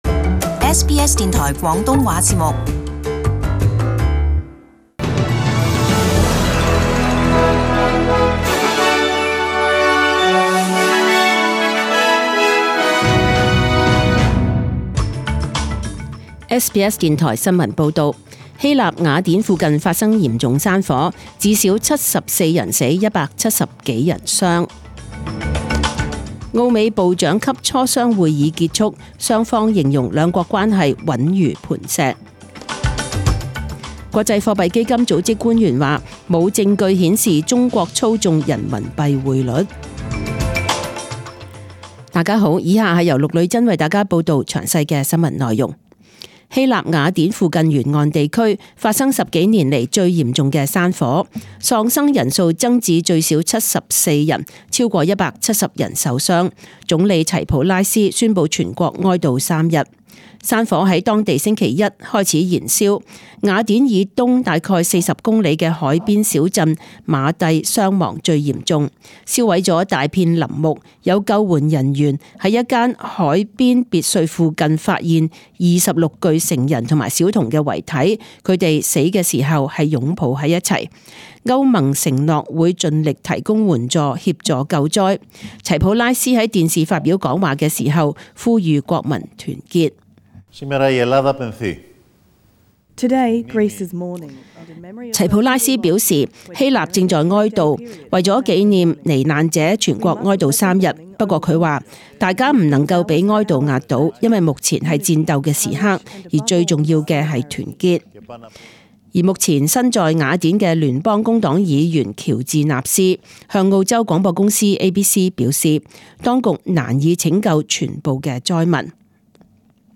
SBS中文新闻 （七月二十五日）
请收听本台为大家准备的详尽早晨新闻。